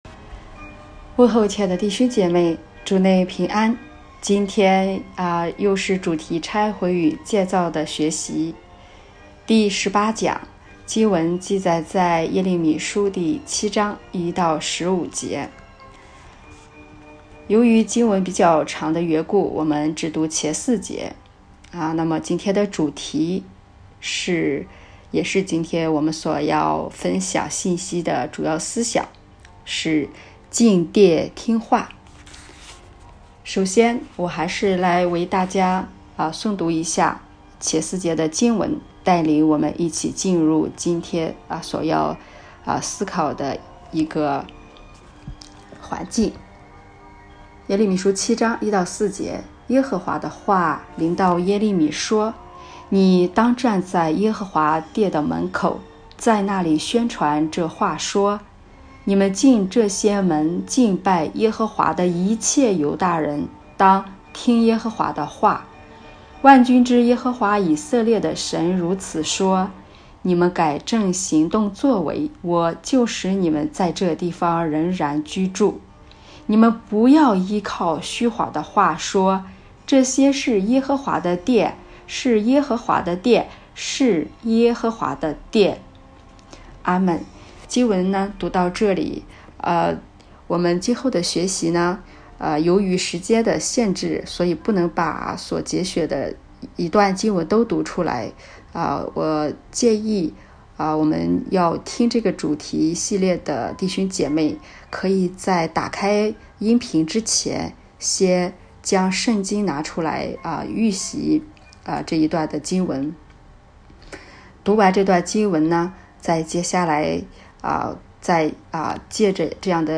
《拆毁与建立第18讲——进殿听话》 证道